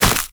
Sfx_creature_penguin_hop_land_03.ogg